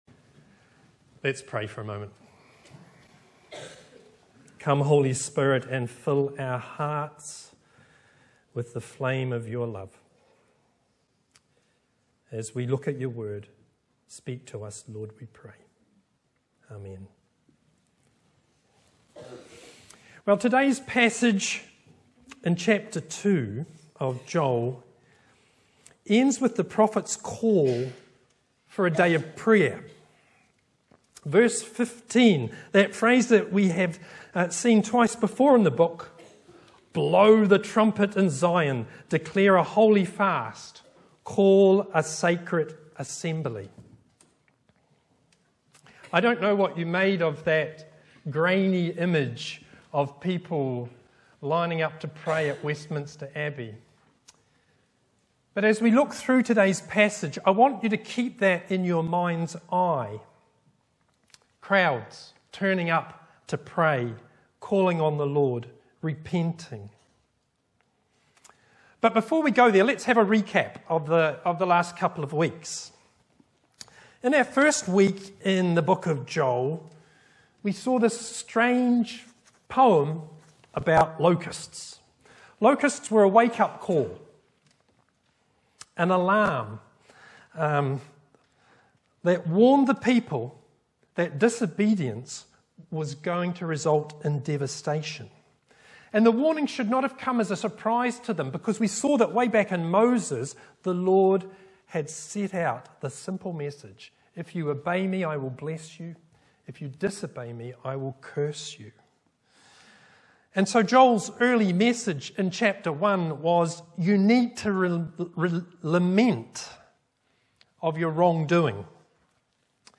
May 07, 2023 Rend your heart MP3 Subscribe to podcast Notes Sermons in this Series Preached on: Sunday 7th May 2023 The sermon text is available as subtitles in the Youtube video (the accuracy of which is not guaranteed).
Bible references: Joel 2:12-17 Location: Brightons Parish Church